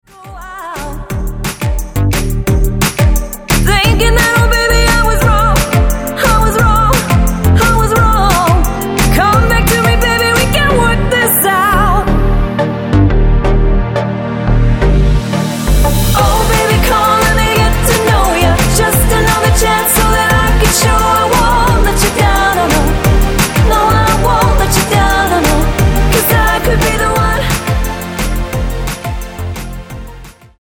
Tonart:F Multifile (kein Sofortdownload.
Die besten Playbacks Instrumentals und Karaoke Versionen .